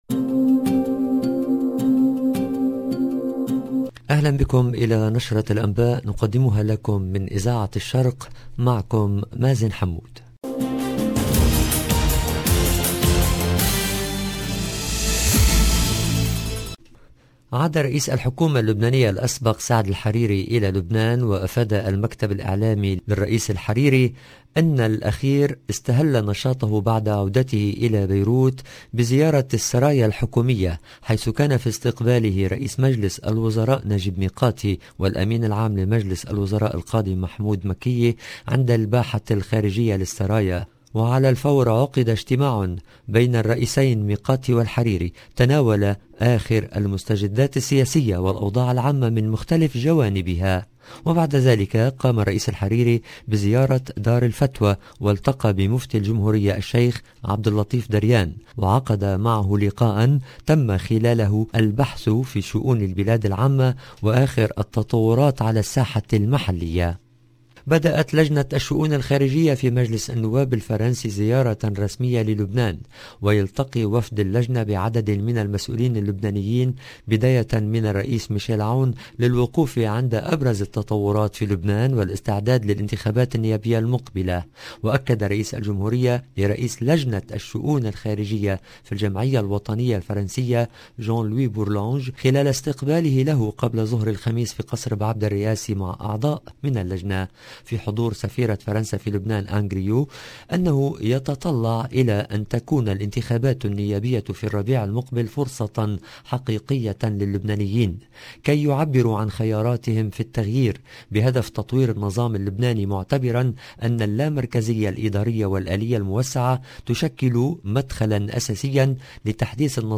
LE JOURNAL DU SOIR EN LANGUE ARABE DU 20/01/22